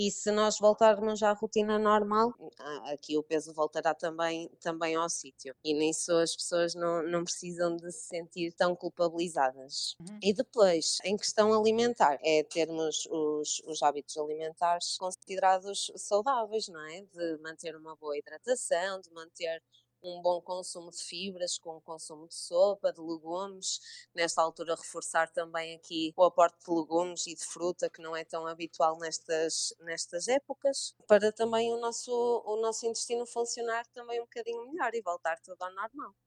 A nutricionista aconselha, vivamente, as pessoas a regressarem à sua rotina, a uma alimentação saudável e também a manter uma boa hidratação: